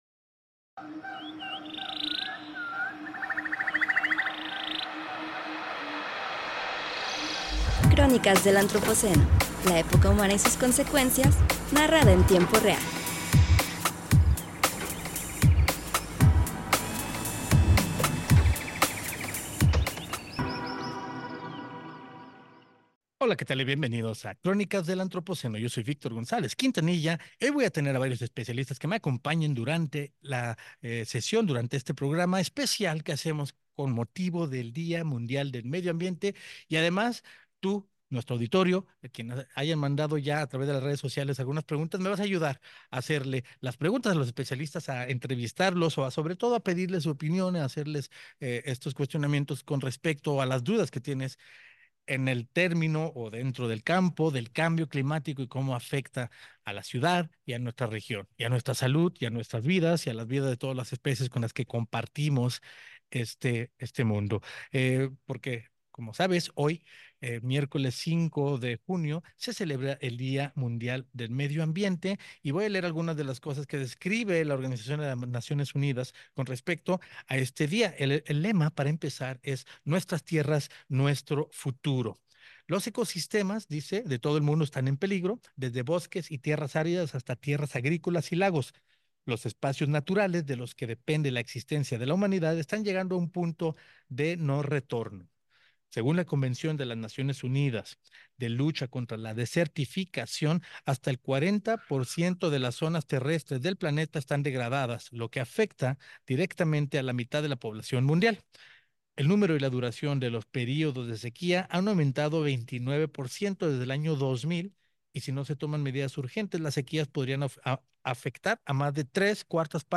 En este episodio especial en el marco del Día Mundial del Medio Ambiente, cinco especialistas de la Universidad de Guadalajara nos comparten su pensamiento y trabajo para comprender las implicaciones del cambio climático en el occidente de México.